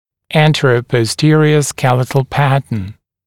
[ˌæntərəpɔs’tɪərɪə ‘skelɪtl ‘pætn][ˌэнтэрэпос’тиэриэ ‘скелит(э)л ‘пэтн]сагиттальный гнатический тип, сагиттальный скелетный тип